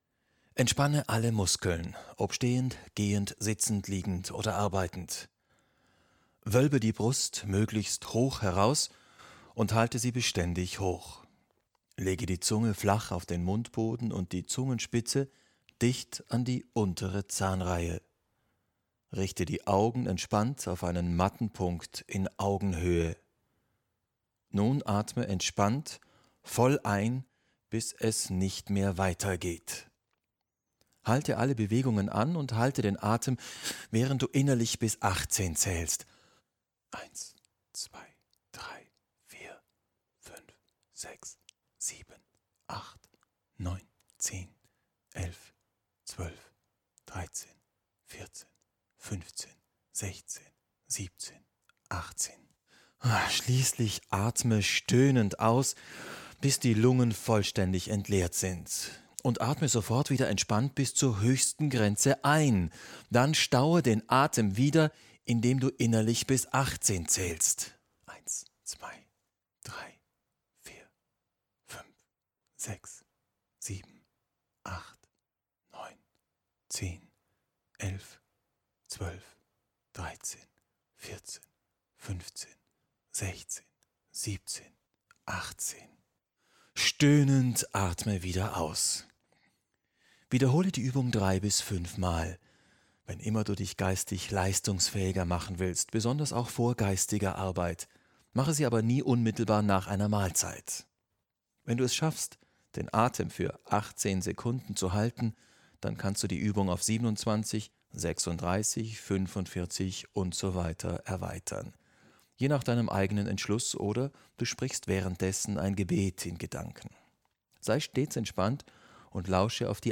(Gefällt Ihnen der gesprochene Text? Haben Sie Interesse an einem kompletten Hörbuch der Atemkunde? Schreiben Sie uns: Kontakt )